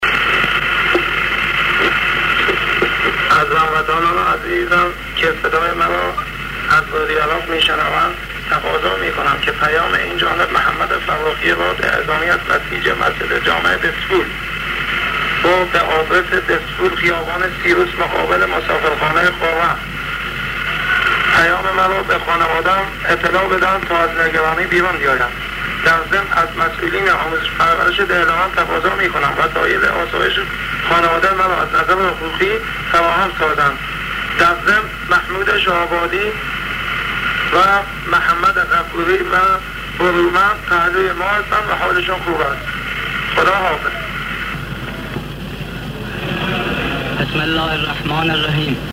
پیام صوتی